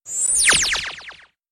Laser Sound Effect